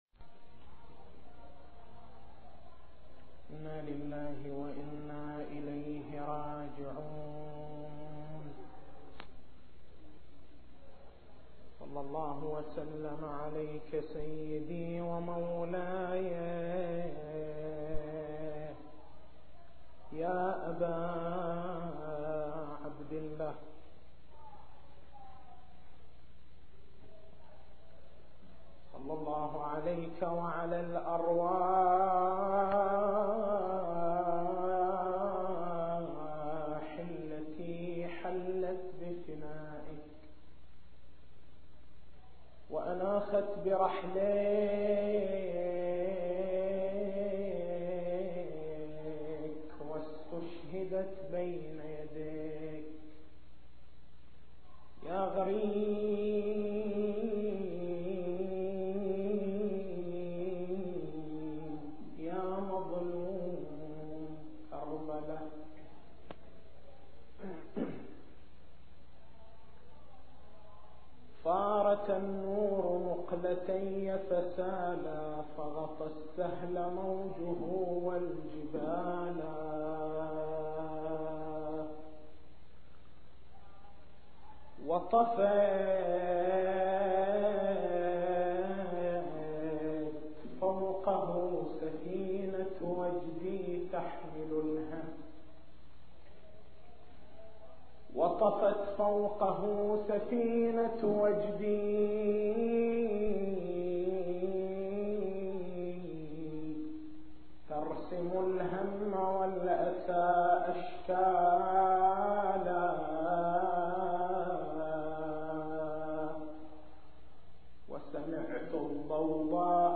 تاريخ المحاضرة: 02/01/1424 نقاط البحث: أهمية مبدأ التقية فلسفة مبدأ التقية لماذا لم يعمل الإمام الحسين (ع) بالتقية؟